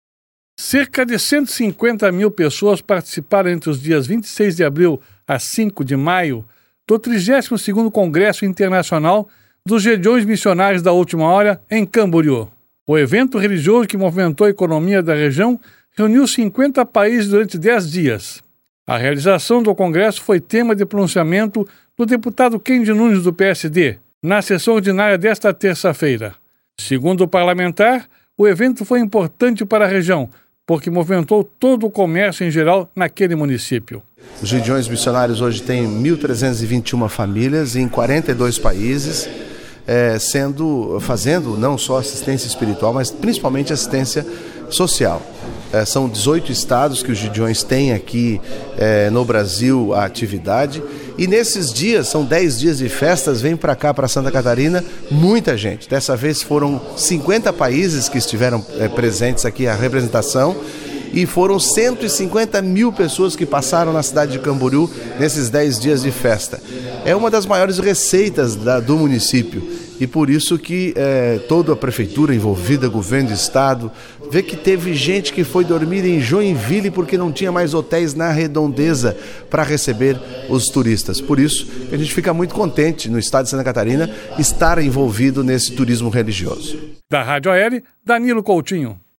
A realização do Congresso foi tema do pronunciamento do deputado Kennedy Nunes (PSD), na Sessão Ordinária desta terça-feira (06).